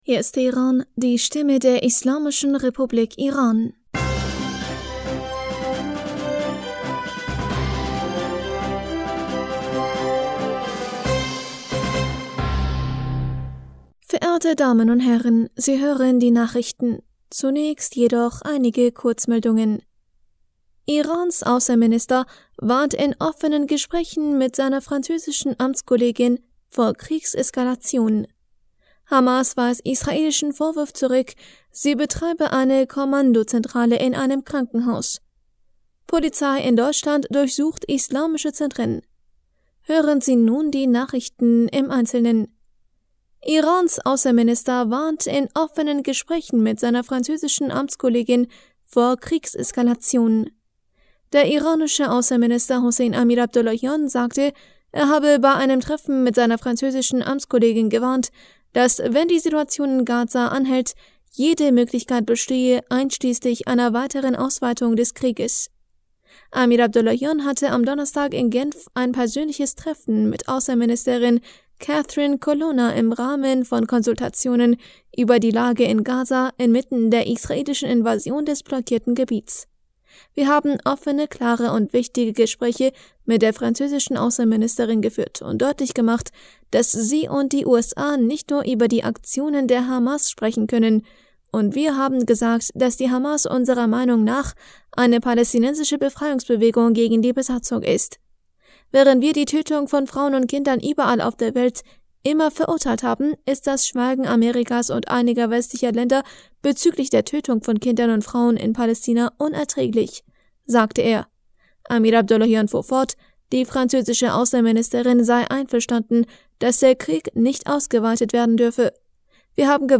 Nachrichten vom 17. November 2023